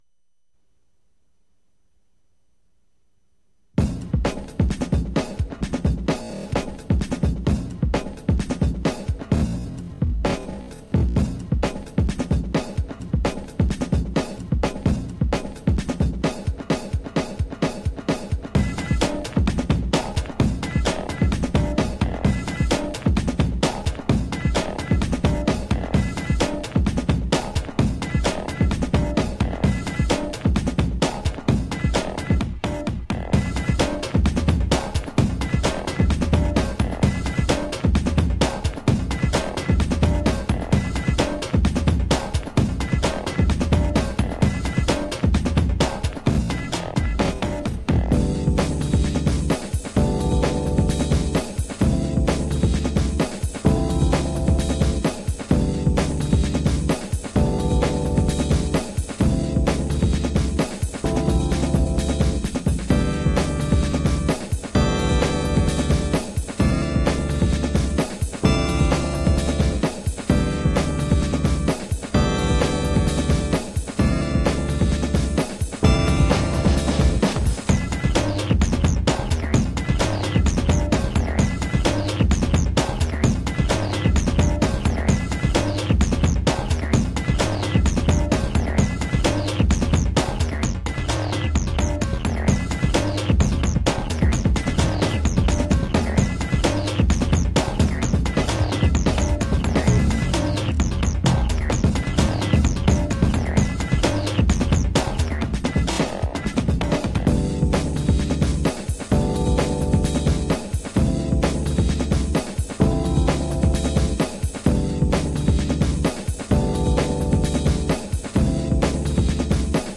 Recorded from tape, this is the first digital version.